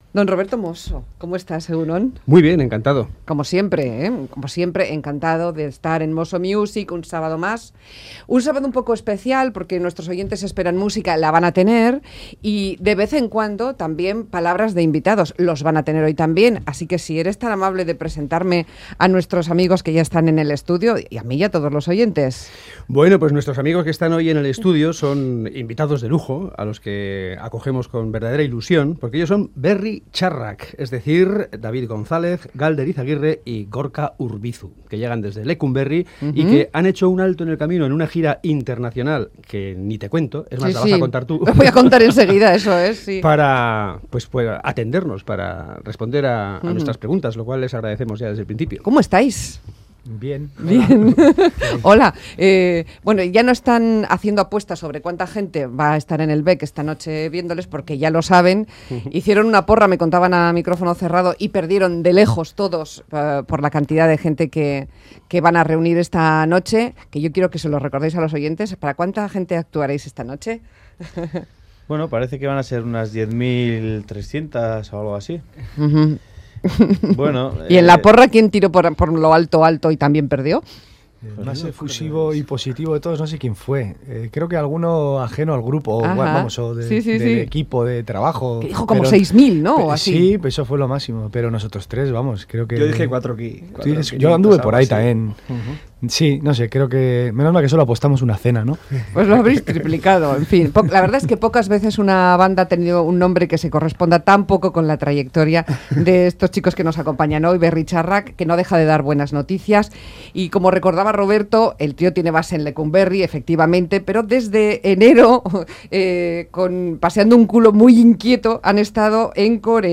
Audio: Berri Txarrak sigue dando buenas noticias con su nuevo disco 'Infrasoinuak', ENTREVISTA BERRI TXARRAK, El grupo navarro esta noche en el BEC dando un multitudinario concierto.